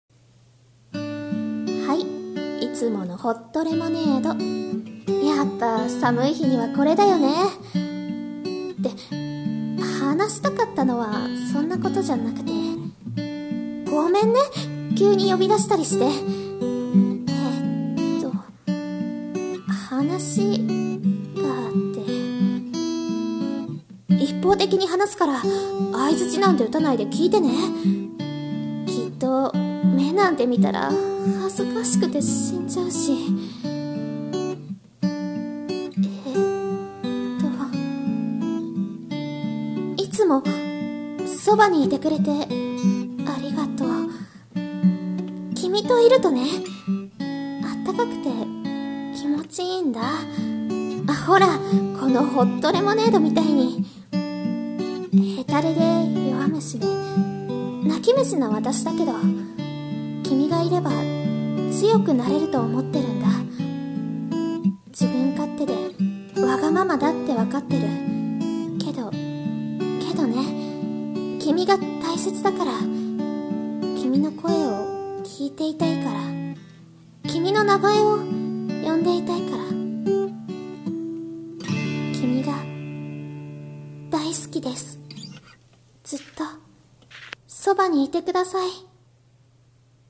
【声劇】ホットレモン